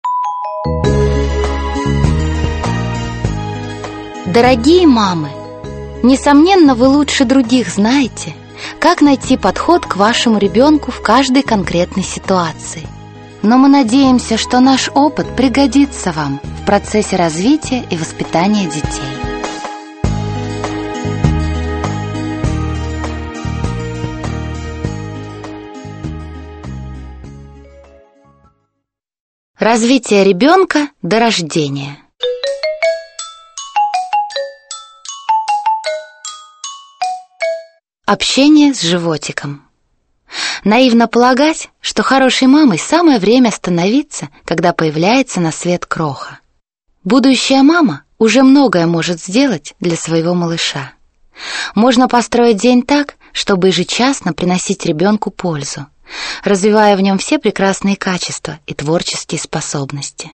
Аудиокнига Гармоничное развитие ребенка от 0 до 3 лет | Библиотека аудиокниг